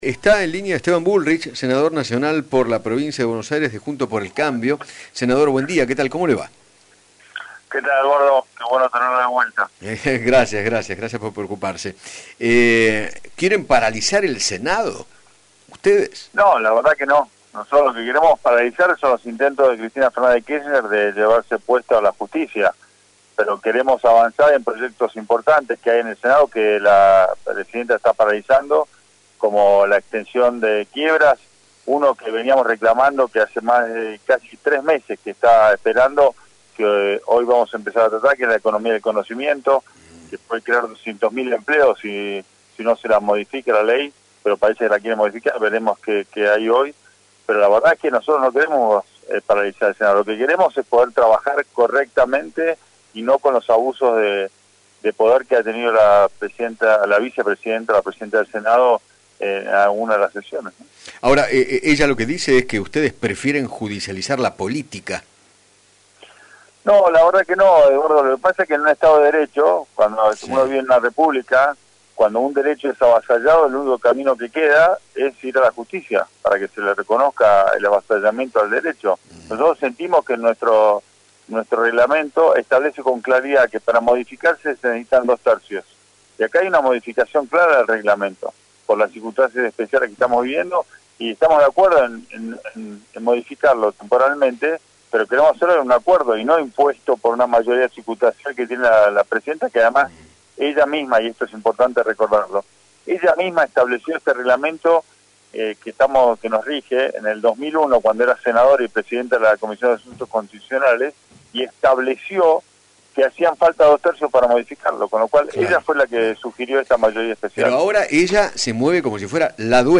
Esteban Bullrich, Senador Nacional por la Provincia de Buenos Aires, dialogó con Eduardo Feinmann sobre la acusación de Cristina Fernández contra el bloque de Juntos por el Cambio de querer “paralizar el Senado”. Además, se refirió a la quita de un punto de la coparticipación a la Ciudad y consideró que “no resuelve el problema de fondo, es un manotazo de ahogado de un Gobierno que no tiene un plan económico”.